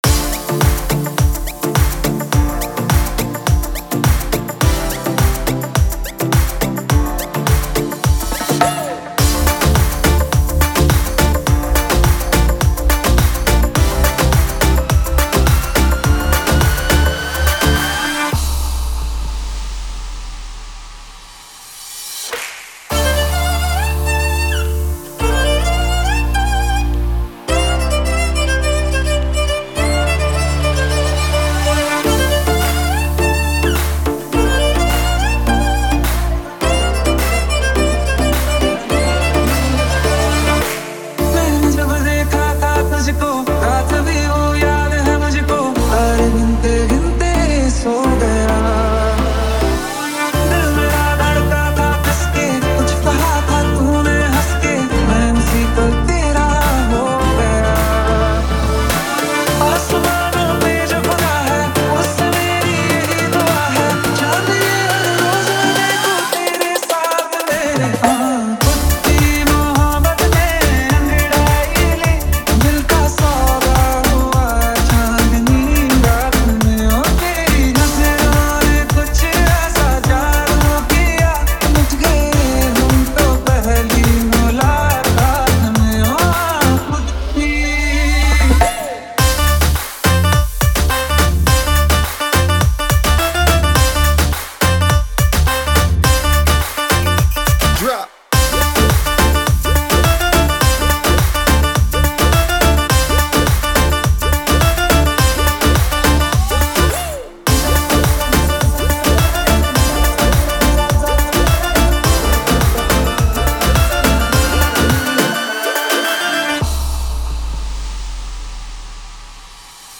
Bollywood Remix
Category: New Odia Nd Sambalpuri Tapori Hits Dj Songs 2021